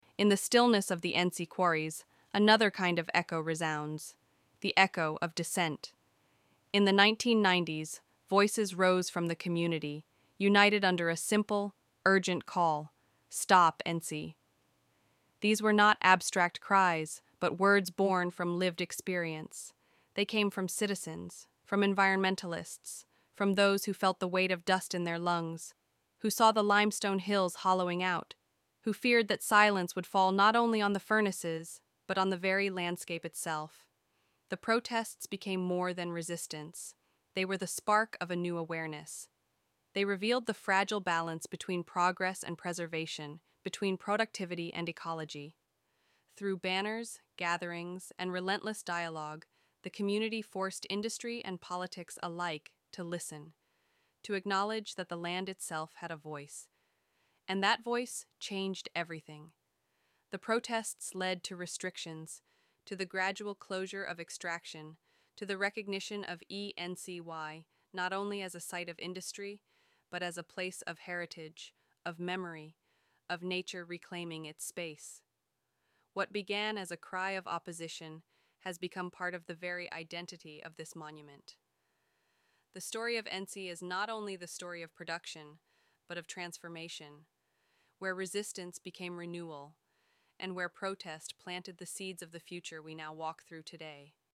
Let the audio guide be your companion, and allow me to accompany you in this journey.